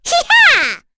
Cecilia-Vox_Attack4.wav